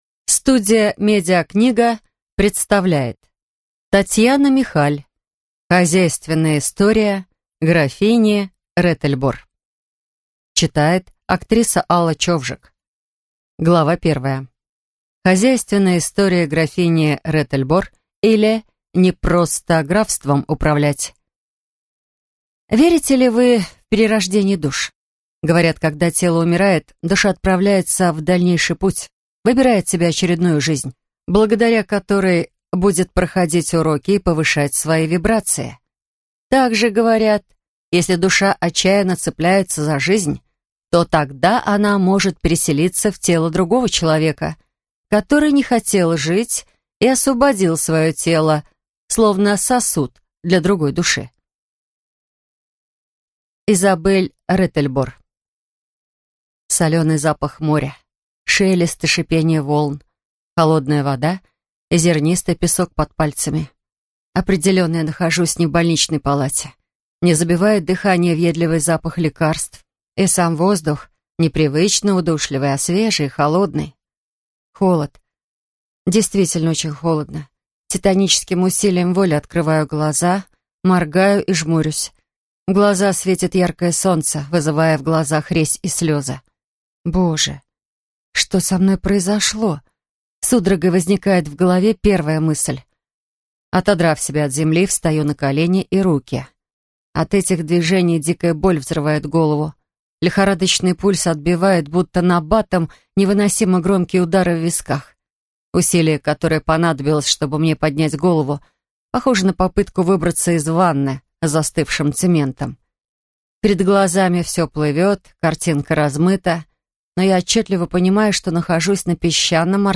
Аудиокнига Хозяйственная история графини Ретель-Бор | Библиотека аудиокниг